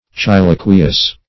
Search Result for " chylaqueous" : The Collaborative International Dictionary of English v.0.48: Chylaqueous \Chy*la"que*ous\, a. [Chyle + aqueous.]